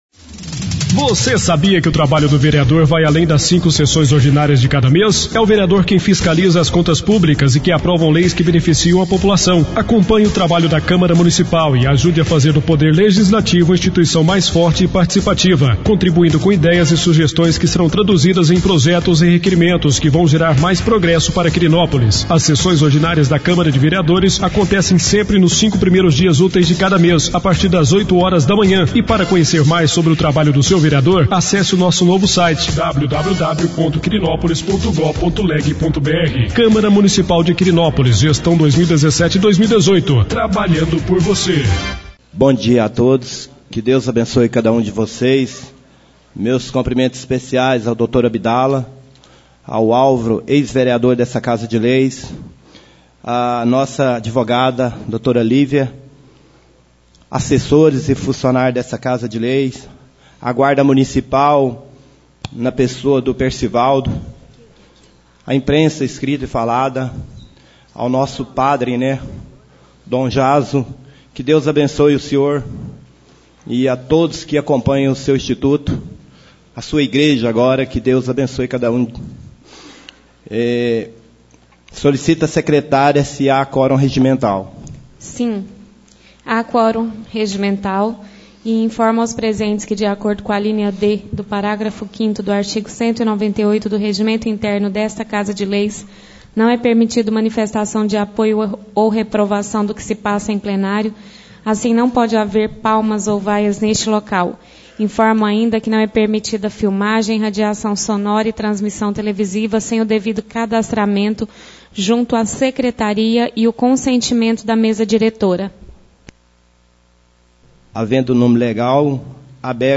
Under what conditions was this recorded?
2ª Sessão ordinária do Mês de Agosto 2017